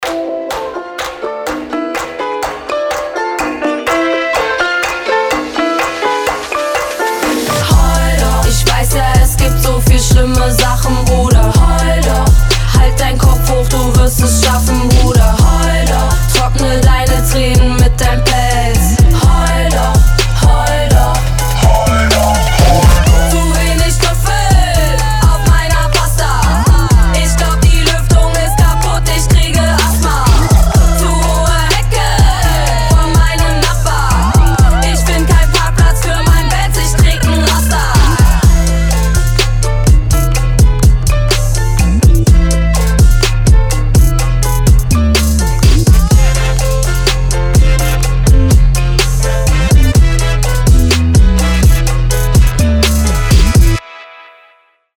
• Качество: 320, Stereo
Хип-хоп
dance
Trap
женский рэп
немецкий рэп